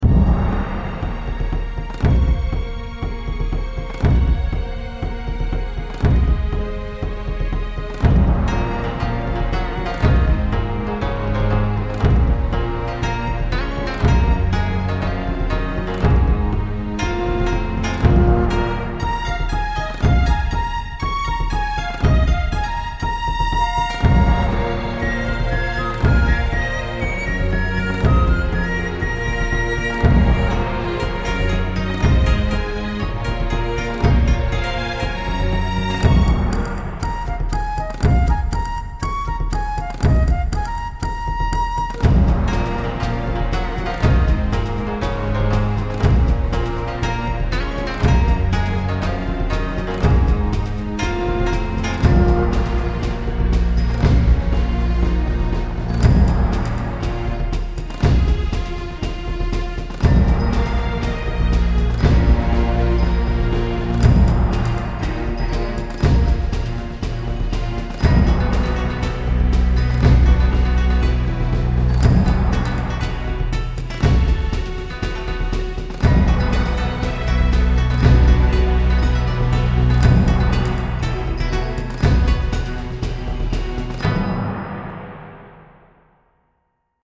Un joli travail, autant pour l'orchestre que pour les instrus ethniques même si les flutes auraient méritées un peu plus de reverb.